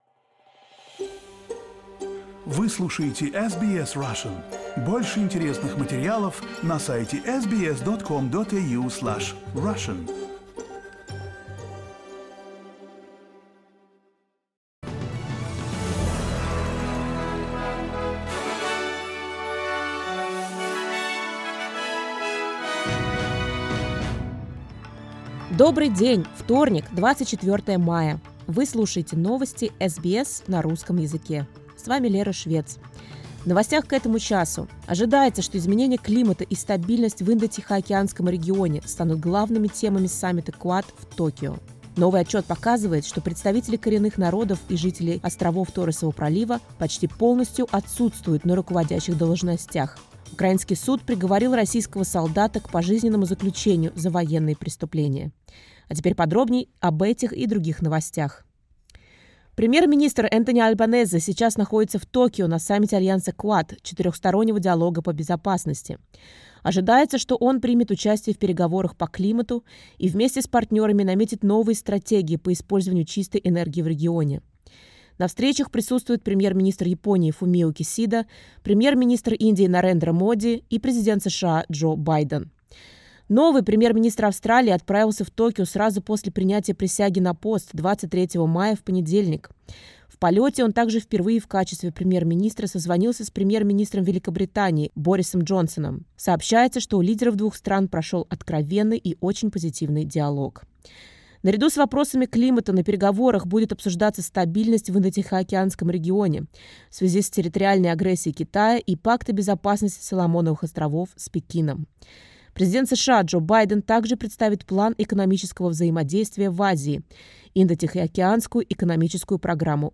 SBS news in Russian — 24.05.22